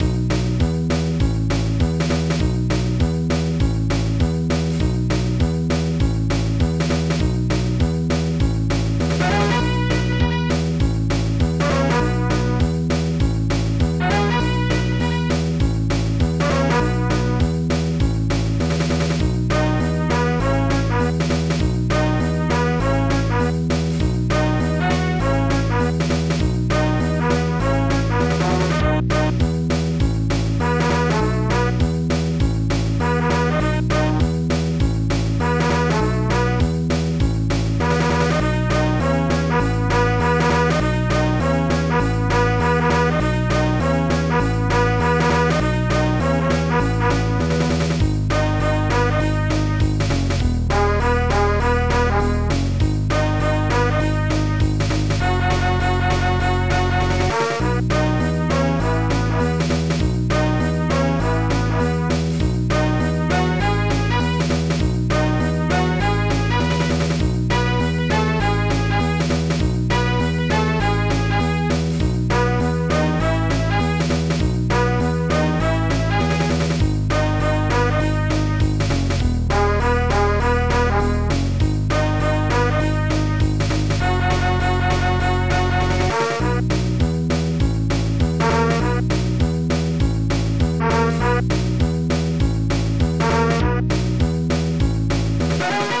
blues
Instruments bigBass1 BASSDRUM snare1 hhopen1 brass pianohi1